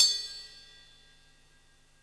Ride_Jazz.wav